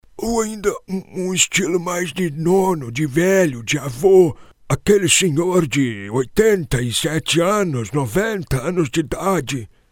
CARICATO VELHO: